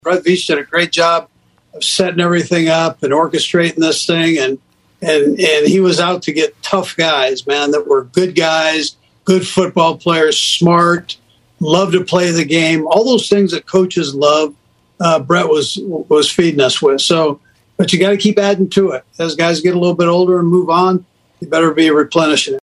Chiefs Coach Andy Reid told the NFL Network, that GM Brett Veach did a great job of re-stocking the cupboard.